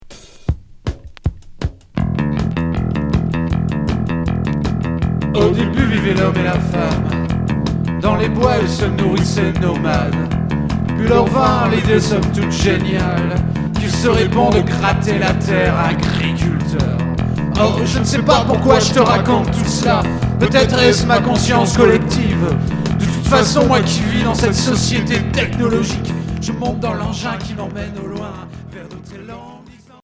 Rock hard Unique 45t retour à l'accueil